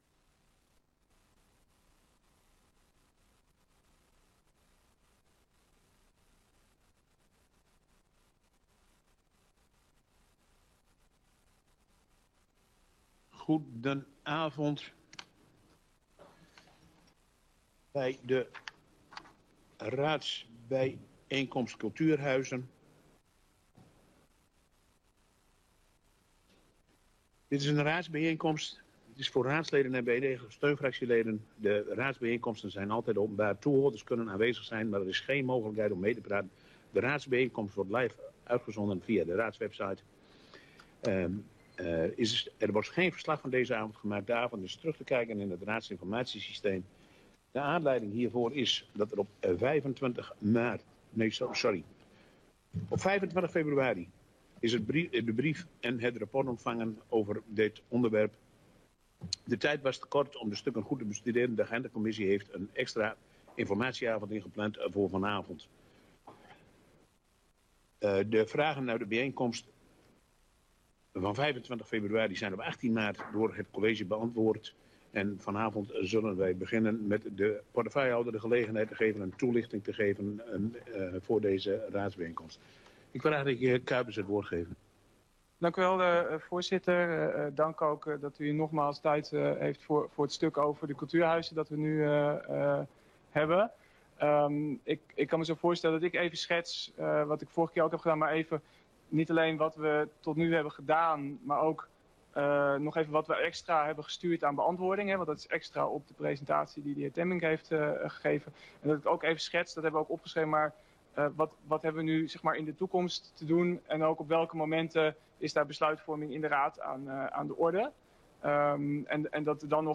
Toelichting door portefeuillehouder en de mogelijkheid tot het stellen van vragen.
Locatie: Raadszaal